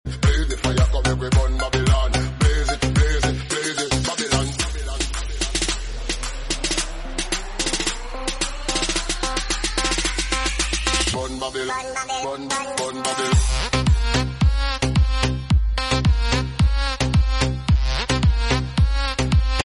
New Attitude Bass Boosted 8k Emotional Music